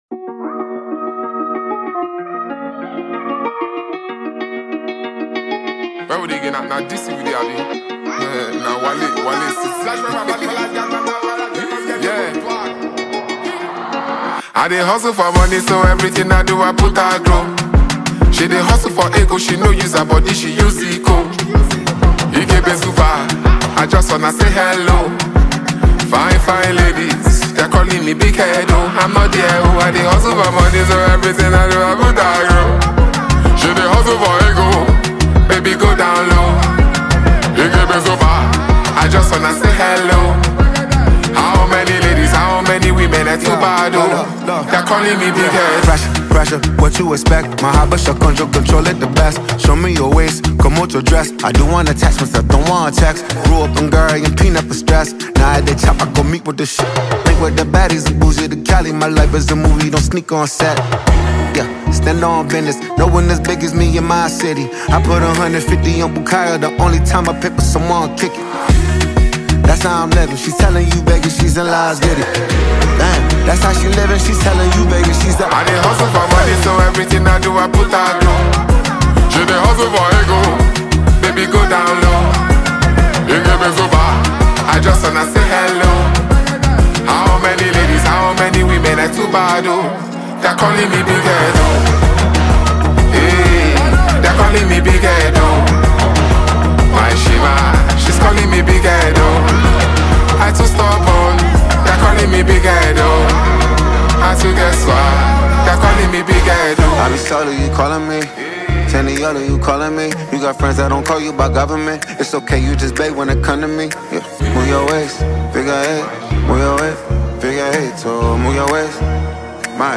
American Hipop rapper, singer-songsmith